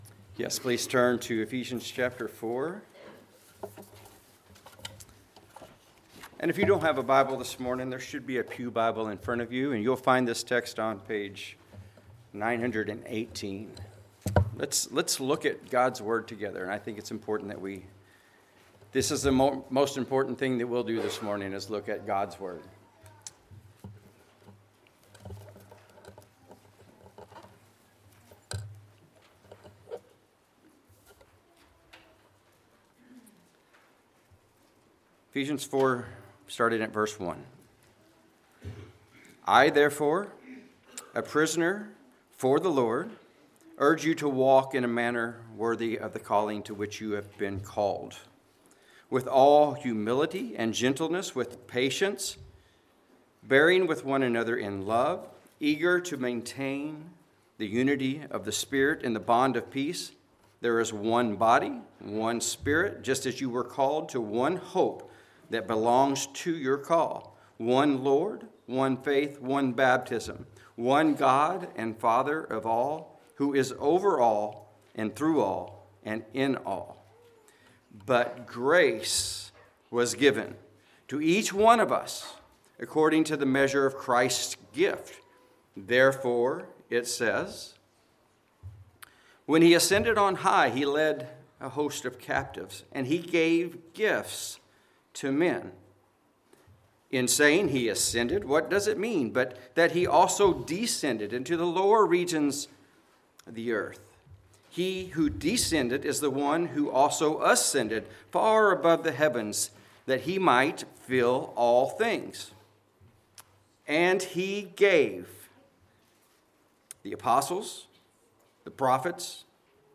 Passage: Ephesians 4:11-14 Service Type: Sunday Morning Related Topics